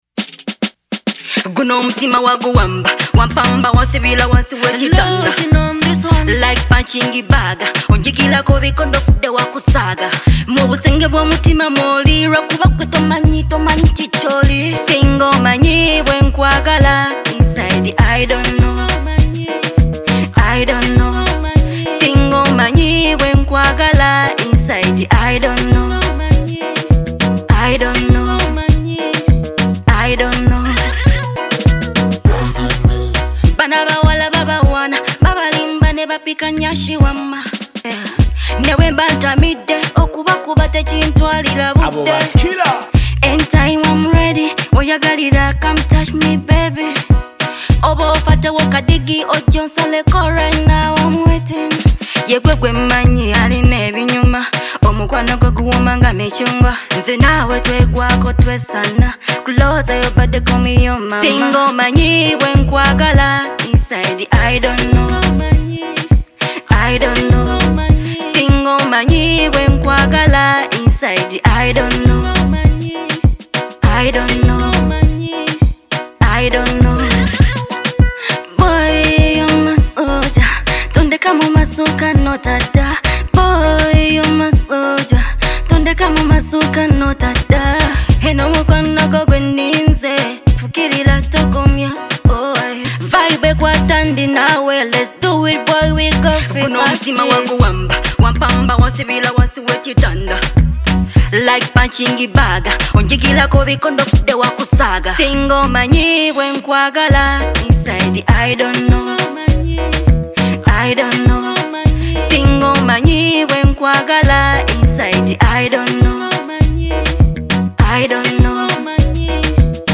Genre: Dance Hall